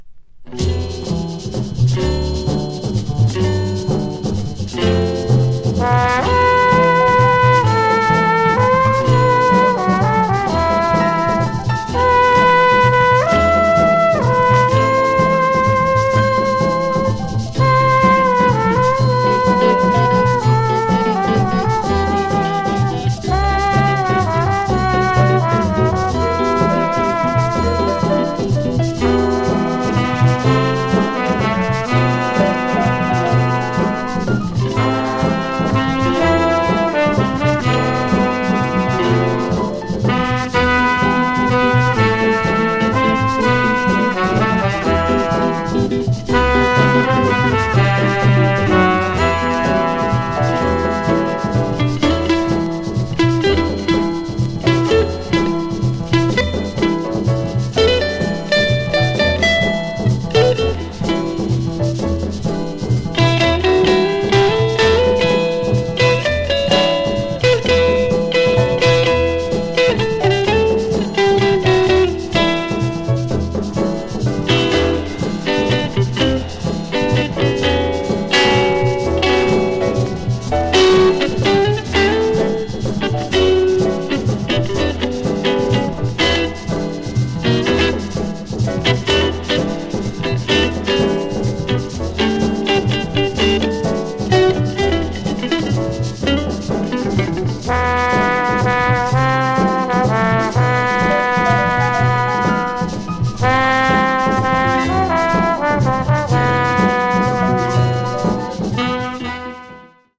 日本の伝統的な曲を取り上げた好作です。
秀逸なアレンジと演奏で多彩に聴かせます。